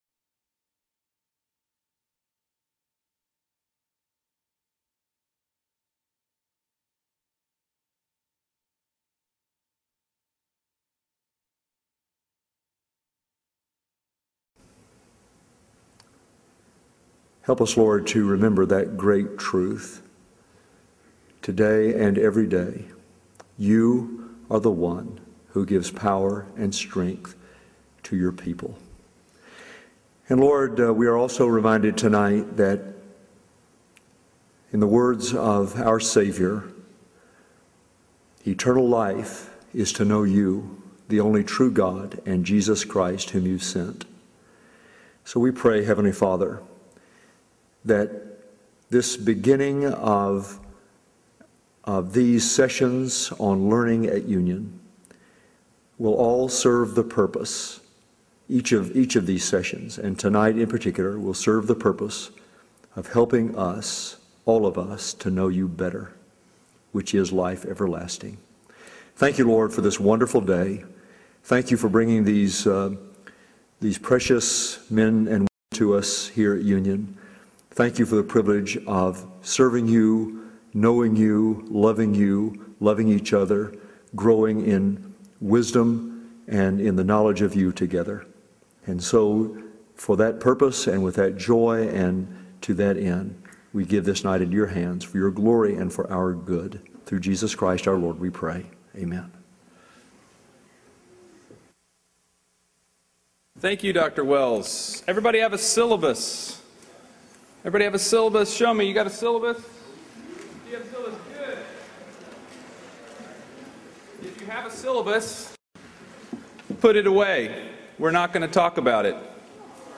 Union University Address: College is Going to Kill You!